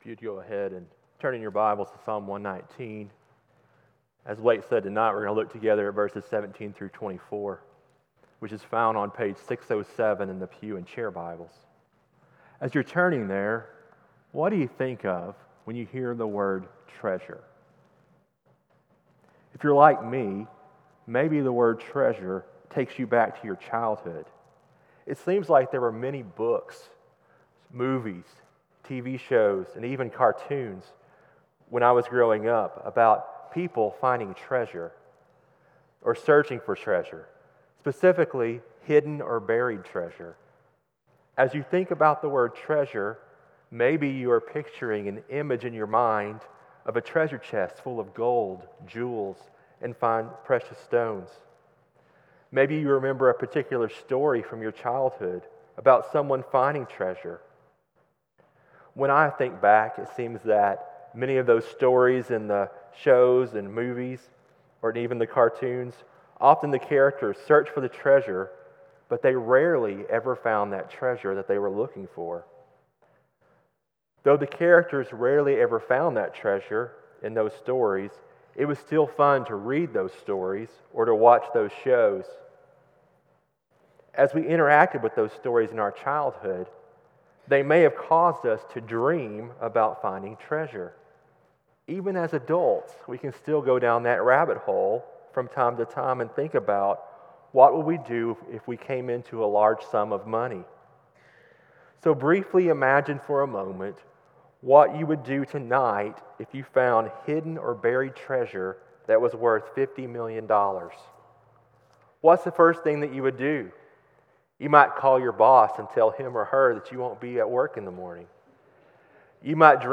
CCBC Sermons